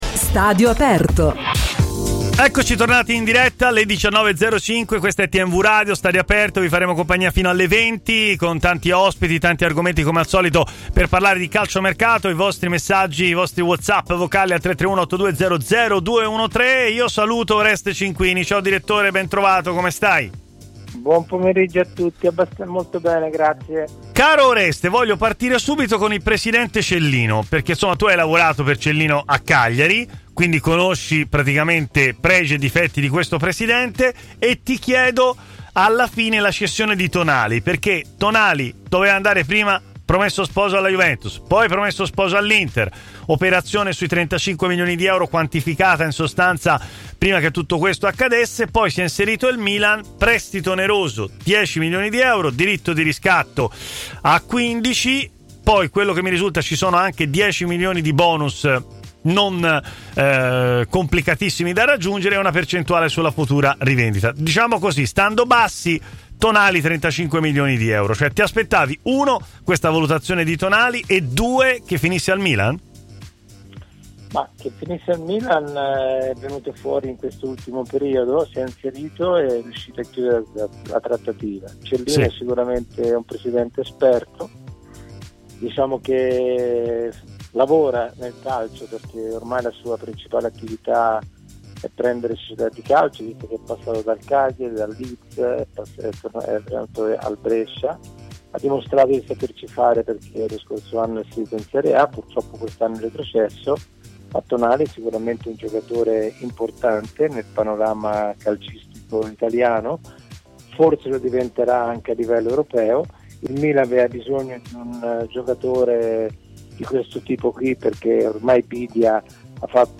intervenuto in diretta a Stadio Aperto, trasmissione di TMW Radio